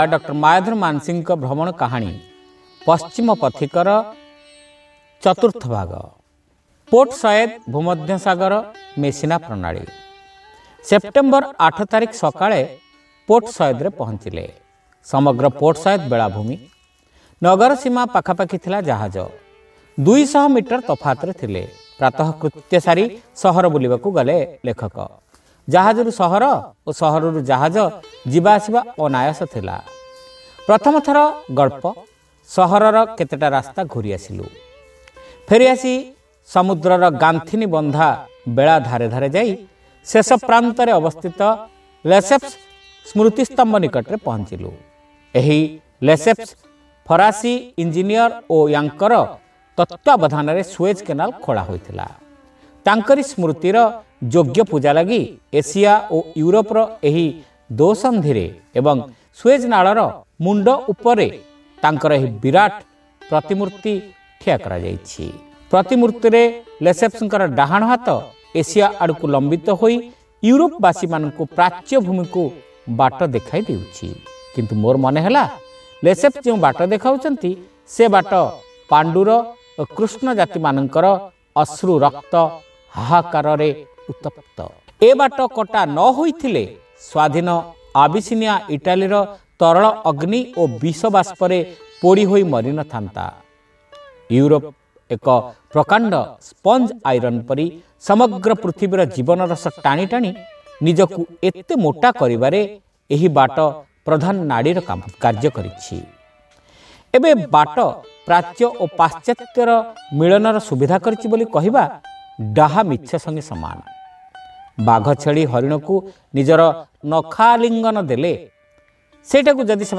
Audio Story : Paschima Pathika ra Sampadita Rupa 4